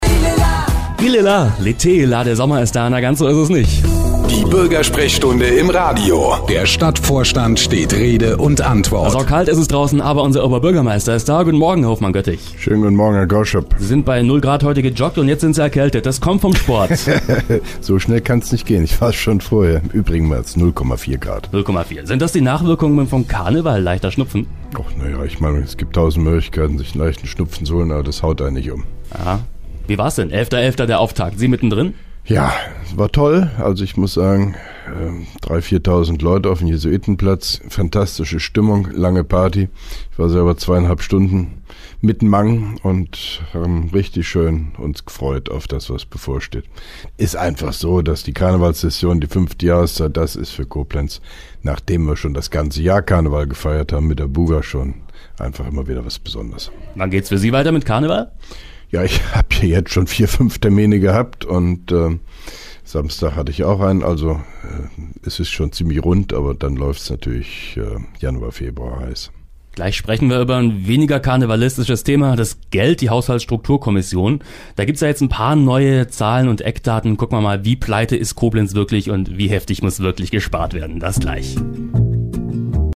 (1) Koblenzer Radio-Bürgersprechstunde mit OB Hofmann-Göttig 15.11.2011
Antenne Koblenz 98,0 am 15.11.2011, ca. 8.25 Uhr (Dauer 01:21 Minuten)